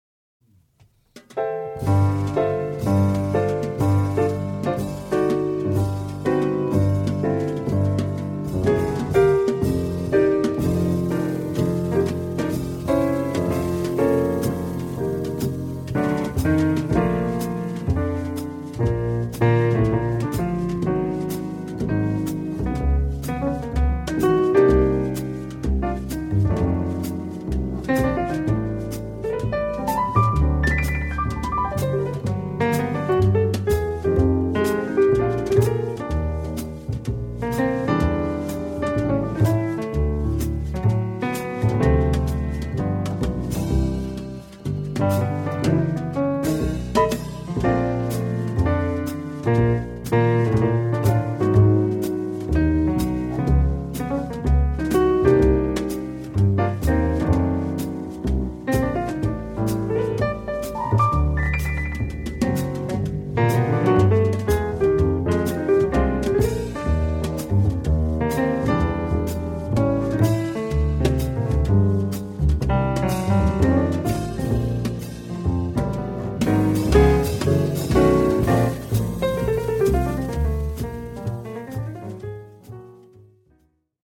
そんな何でもない日常の中で感じる幸せな気持ちが曲になりました。